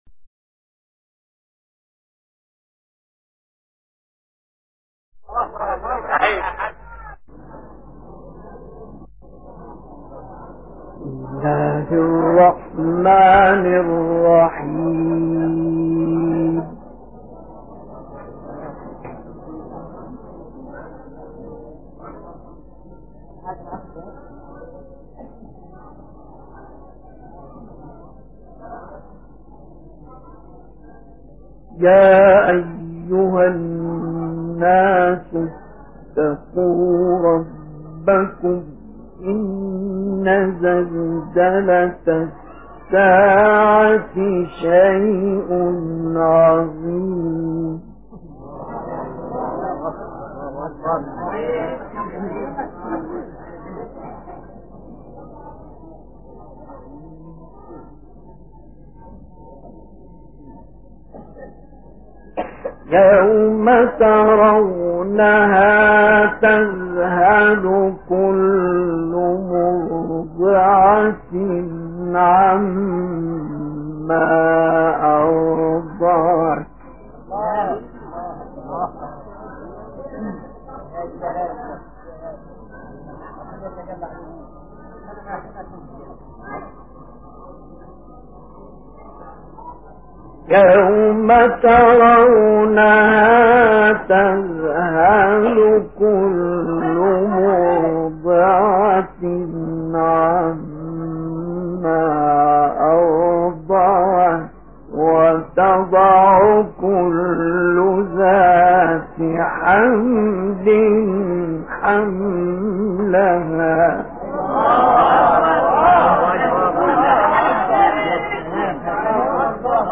تلاوت سوره حج با صوت «مصطفی اسماعیل»
گروه شبکه اجتماعی: تلاوت آیاتی از سوره مبارکه حج که شامل آیه‌های بهاریست، با صوت مصطفی اسماعیل را می‌شنوید.